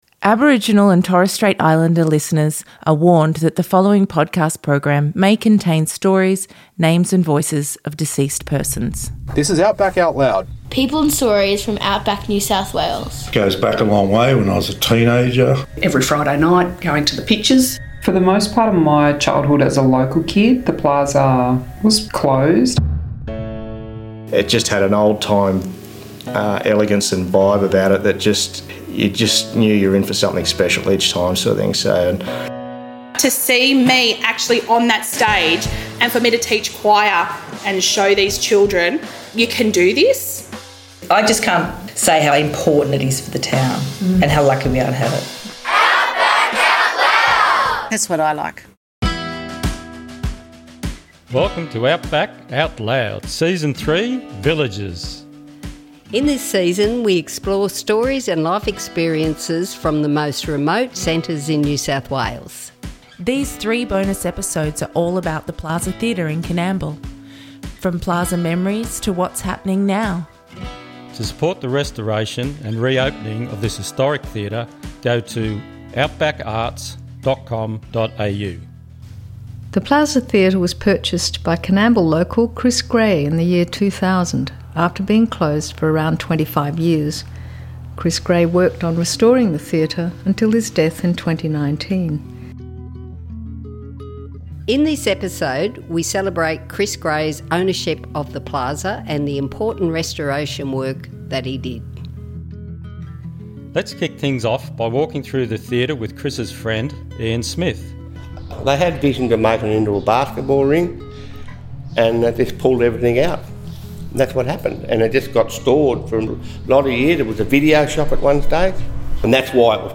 Unfortunately, his untimely passing in 2019 left the theatre again vacant In this Special Episode we hear interviews from people in Coonamble NSW, Wailwan Country. This episode features stories, memories and reflections about The Plaza Theatre which reopened early in 2024.